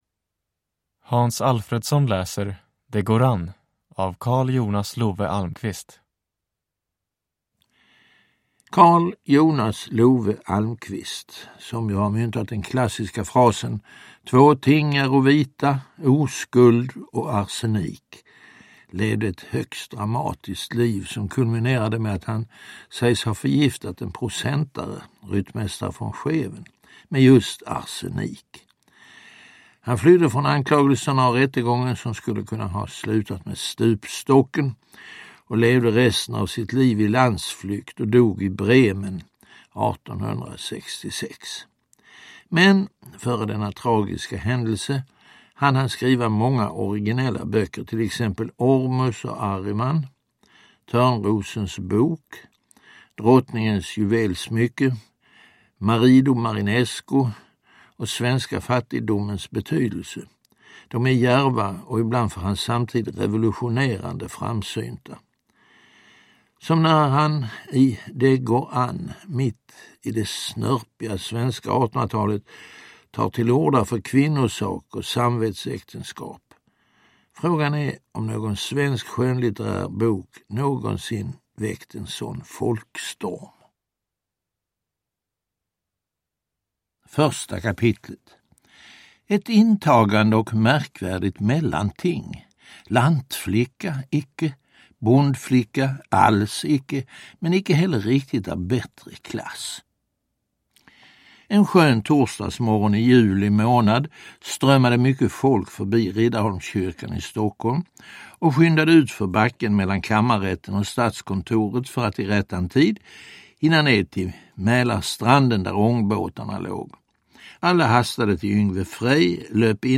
Det går an – Ljudbok – Laddas ner
Uppläsare: Hans Alfredson